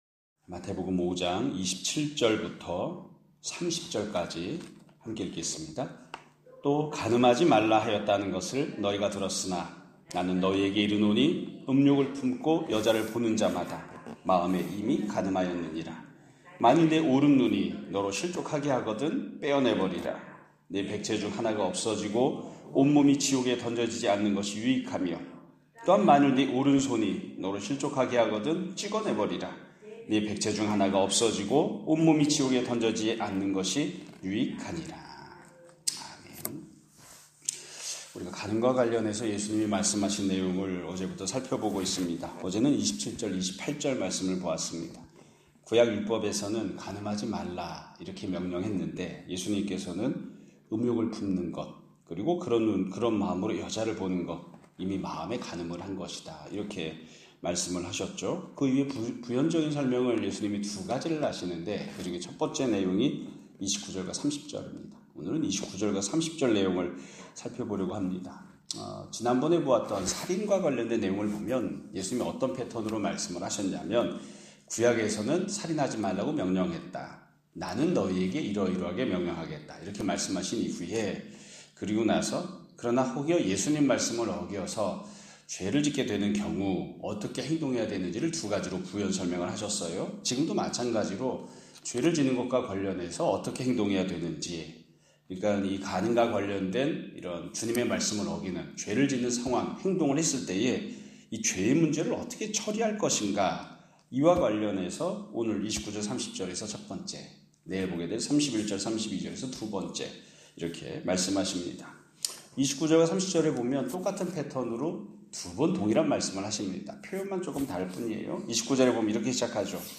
2025년 5월 27일(화요일) <아침예배> 설교입니다.